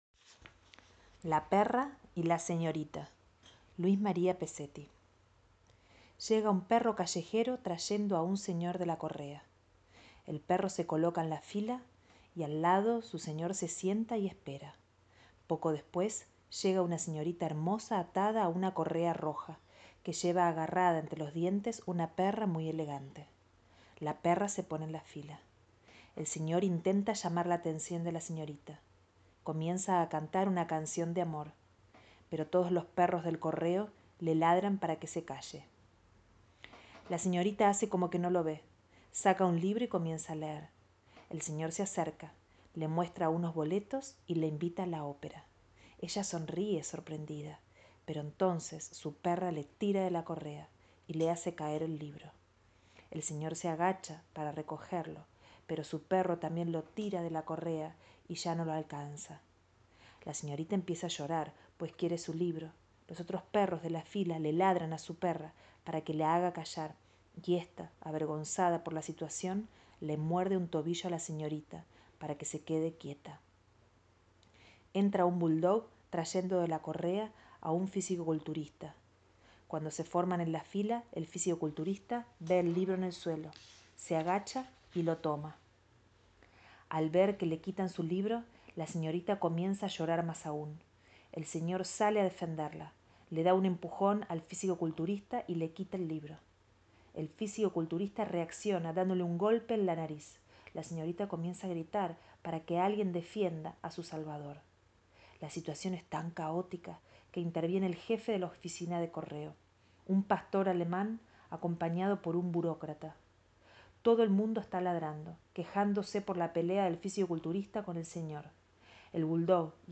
cuento para la infancia